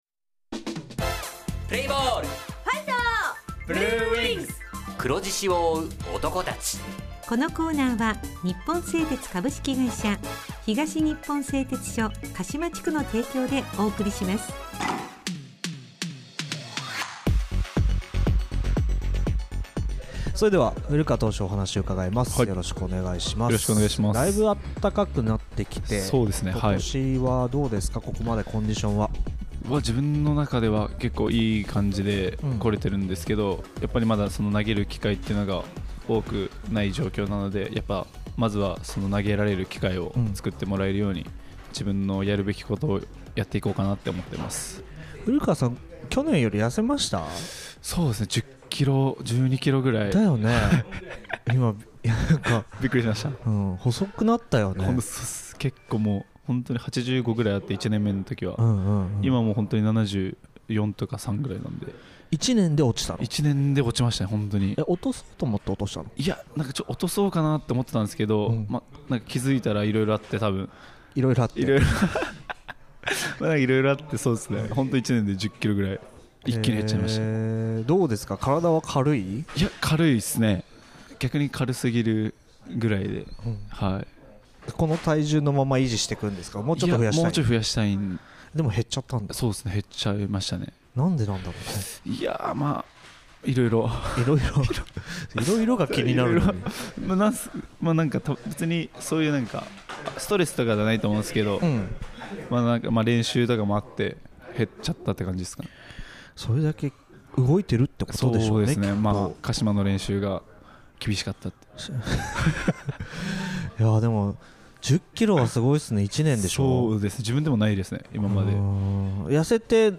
選手インタビュー
地元ＦＭ放送局「エフエムかしま」にて鹿島硬式野球部の番組放送しています。